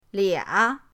lia3.mp3